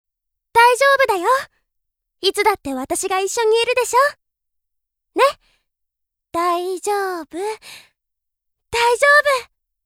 「乱痴「奇｣スカイ」は友人を無くした女子高生が彼女の死に纏わる人に話を聴いて回るR-15短編ボイスドラマです。
明るく優しくあ子に接していた
(い子のみ台詞サンプルが本編とは違うものになっております。)